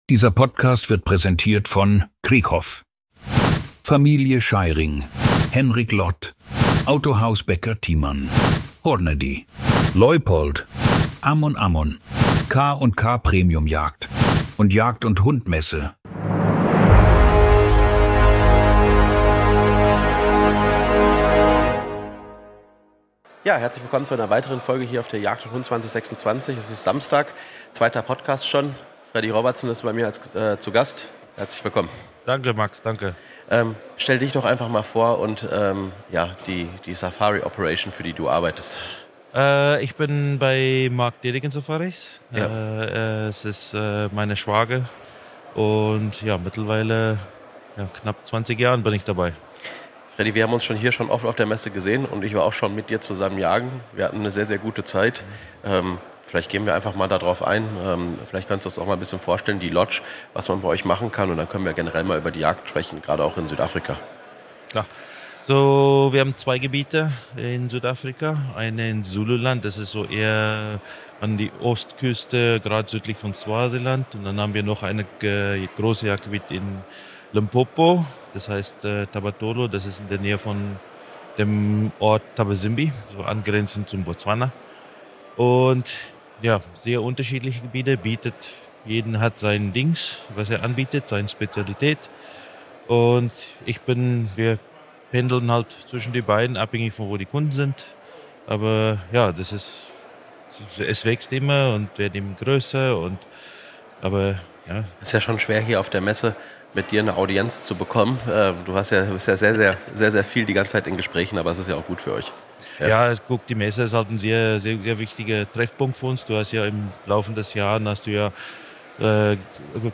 Es geht um intensive Jagdmomente, Nähe zum Wild und die ganz besondere Spannung, die das Bogenjagen mit sich bringt. Außerdem sprechen die beiden über außergewöhnliche Erlebnisse abseits der Jagd: Sightseeing mit Elefanten, Begegnungen in freier Wildbahn und Momente, die man so nur in Afrika erlebt.